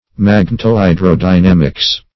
magnetohydrodynamics - definition of magnetohydrodynamics - synonyms, pronunciation, spelling from Free Dictionary
magnetohydrodynamics \mag*net"o*hy`dro*dy*nam"ics\, n.